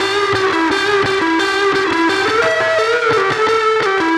Track 02 - Guitar Lead 04.wav